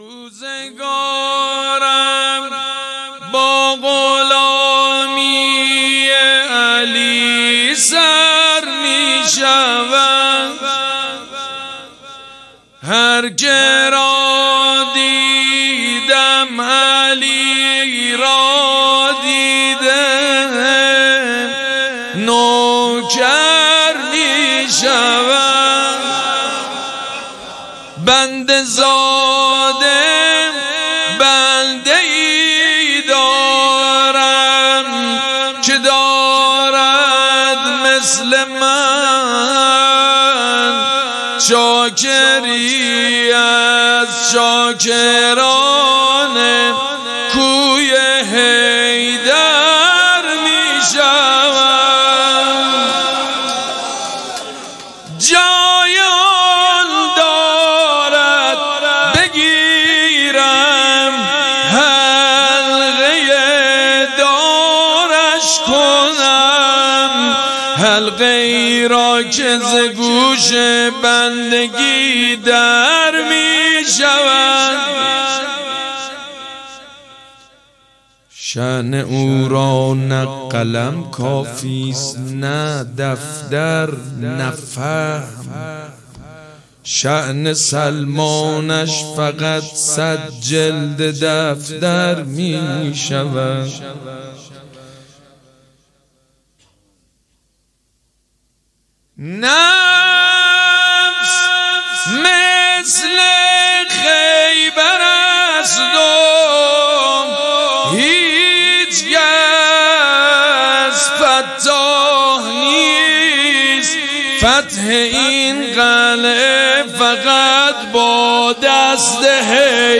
حسینیه ریحانة‌الحسین (سلام‌الله‌علیها)
شعر خوانی
مداح
حاج سید مجید بنی فاطمه